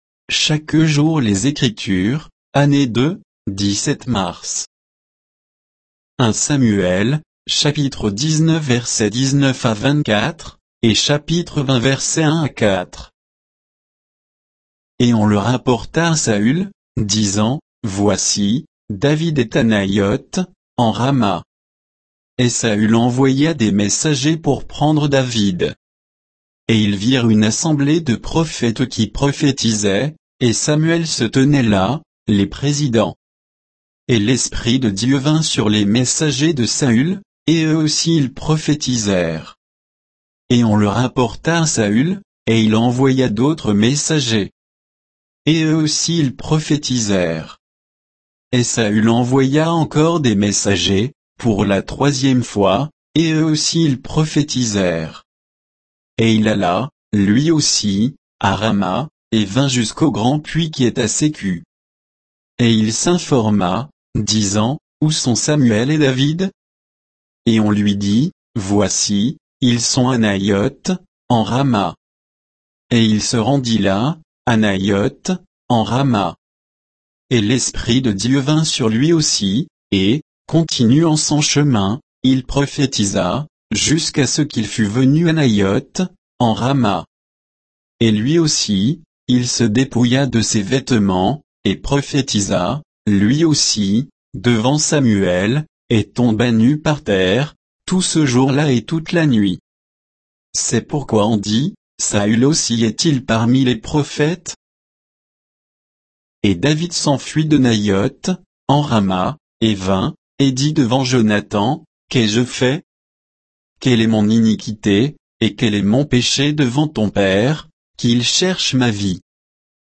Méditation quoditienne de Chaque jour les Écritures sur 1 Samuel 19, 19 à 20, 4